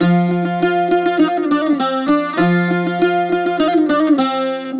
STRINGED INSTRUMENTS